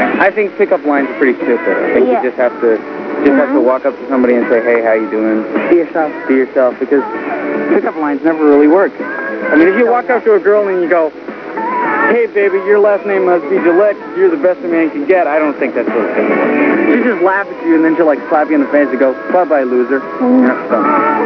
Isaac talking about pick-up lines